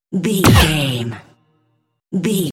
Electronic stab hit trailer
Sound Effects
Atonal
heavy
intense
dark
aggressive